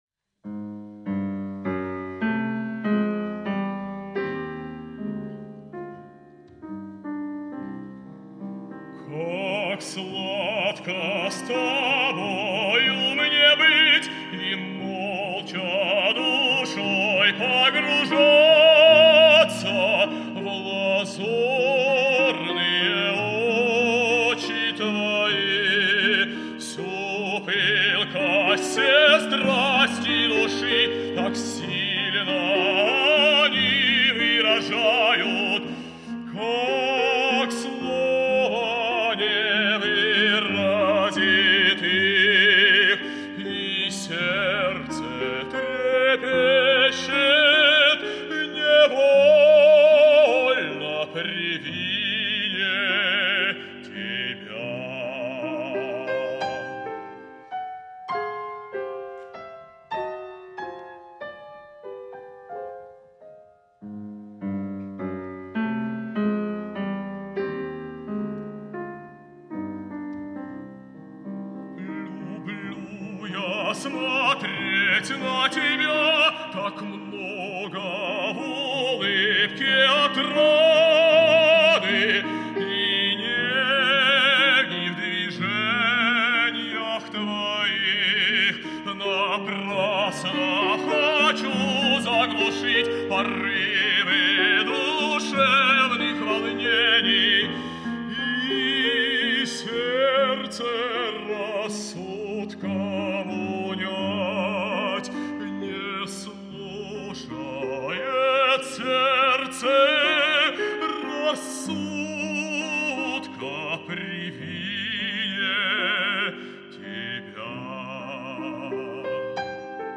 баритон